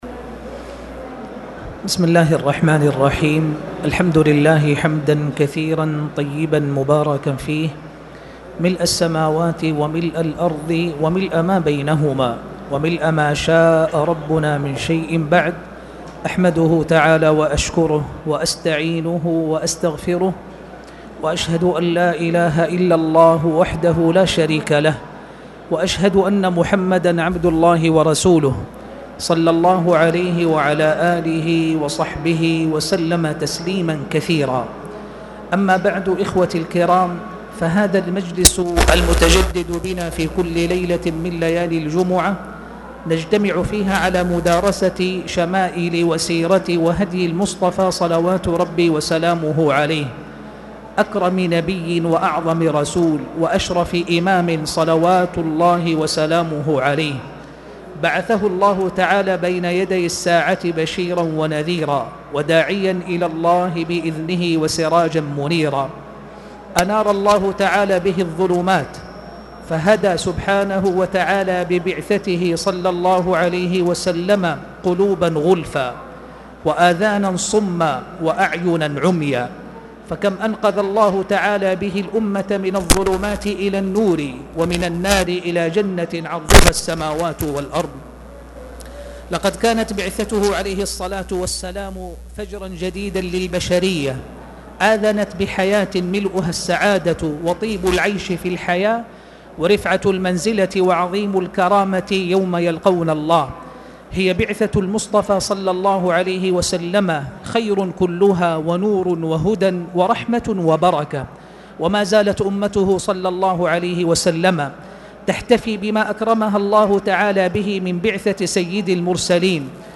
تاريخ النشر ١٧ جمادى الآخرة ١٤٣٨ هـ المكان: المسجد الحرام الشيخ